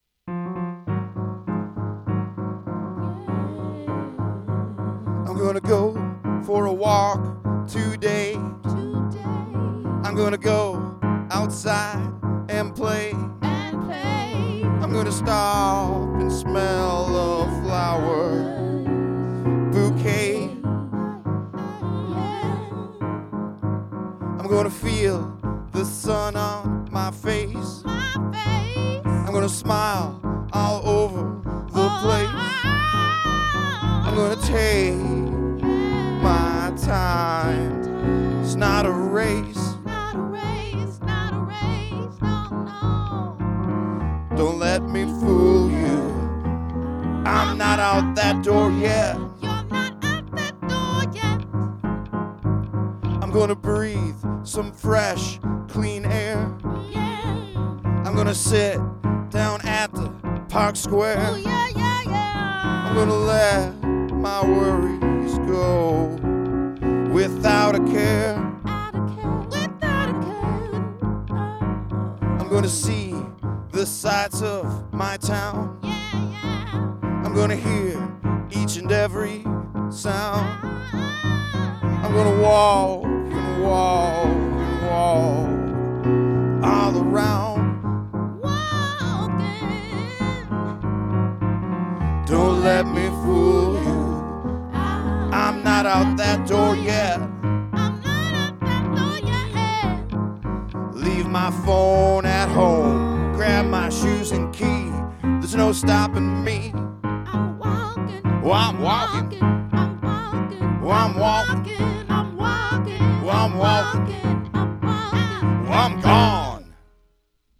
Quick little piano diddy
backup vocals